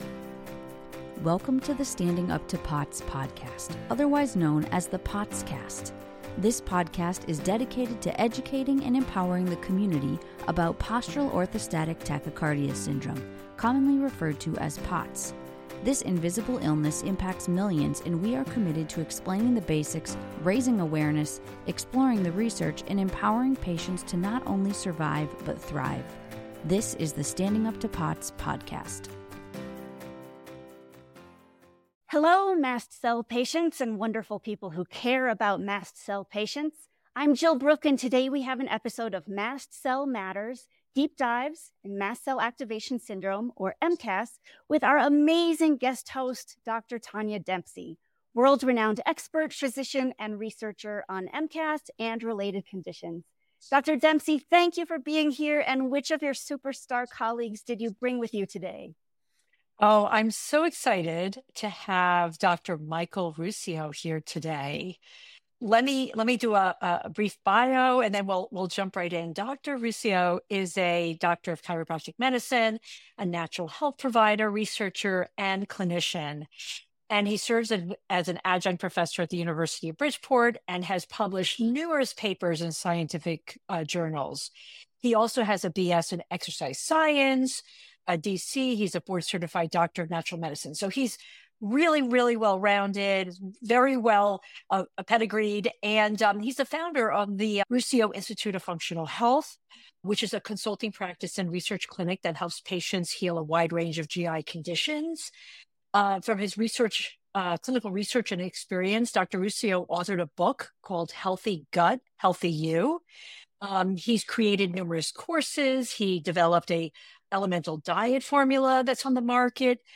This is a great conversation between top clinicians, full of practical tips for patients with SIBO and other GI issues in the context of MCAS.